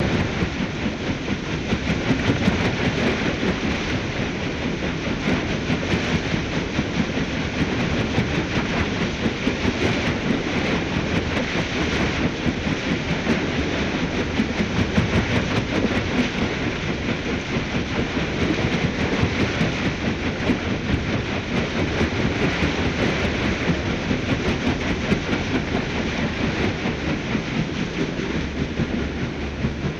Paddle Boat Water Wash